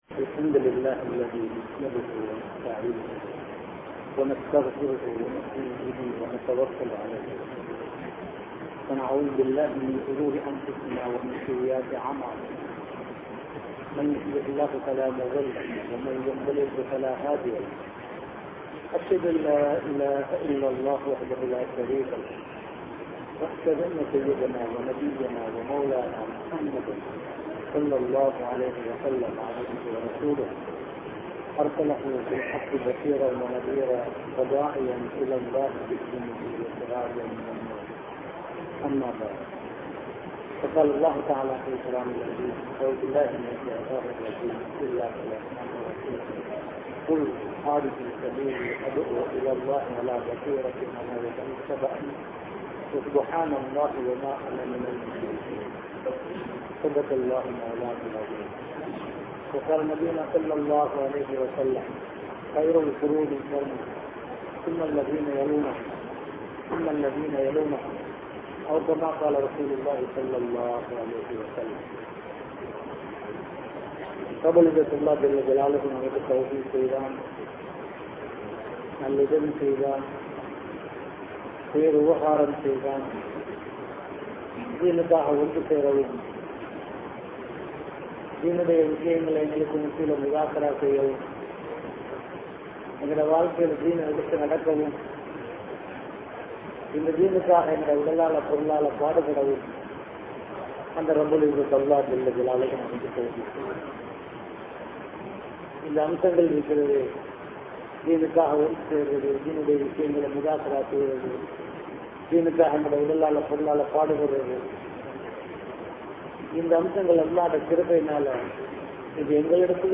Dheenukku Nearam Koduththaal (தீணுக்கு நேரம் கொடுத்தால்) | Audio Bayans | All Ceylon Muslim Youth Community | Addalaichenai